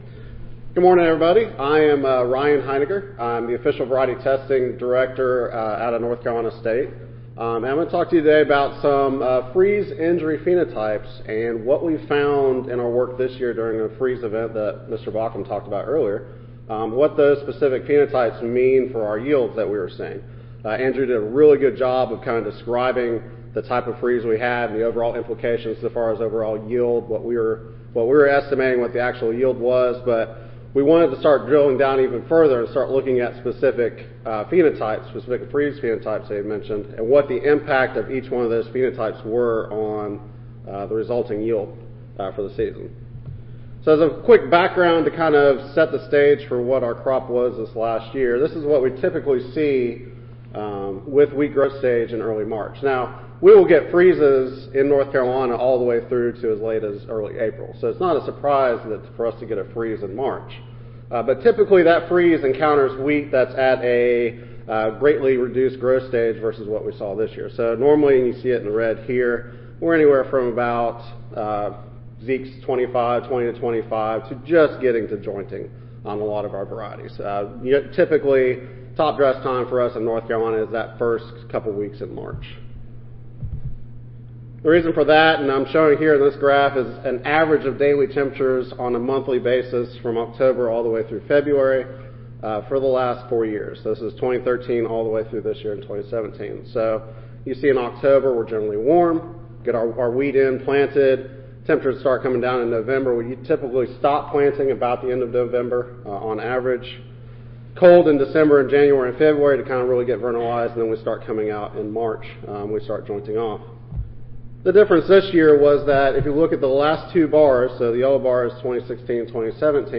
North Carolina State University Audio File Recorded Presentation